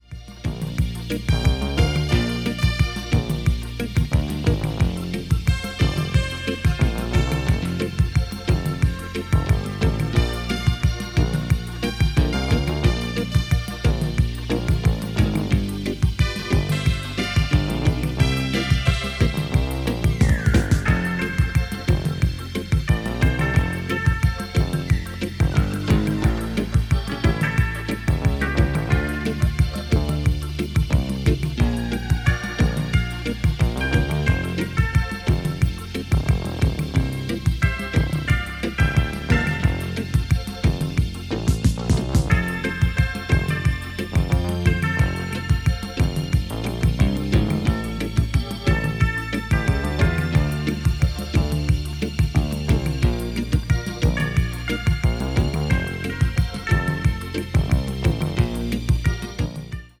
80's synth-tastic library rarity